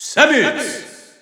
Announcer pronouncing Samus's name in French.
Category:Samus (SSBU) Category:Announcer calls (SSBU) You cannot overwrite this file.
Samus_French_Announcer_SSBU.wav